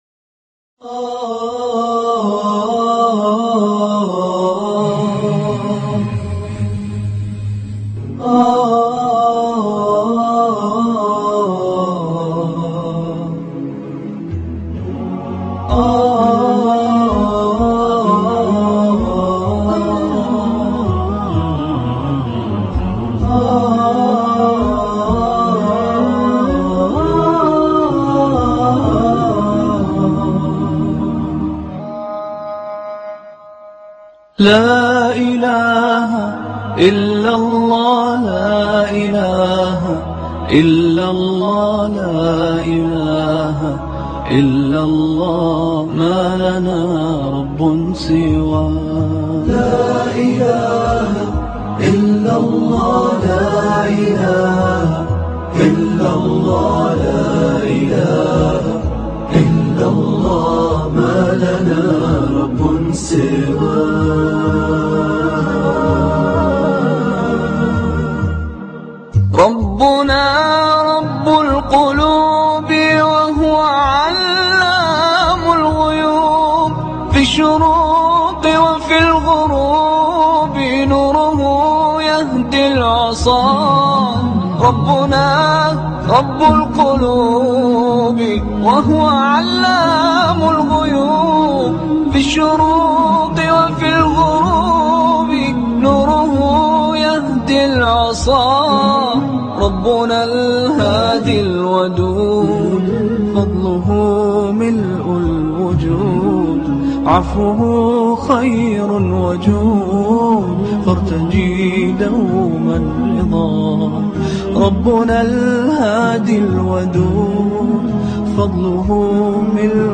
Listen to this beautiful Islamic Nasheed.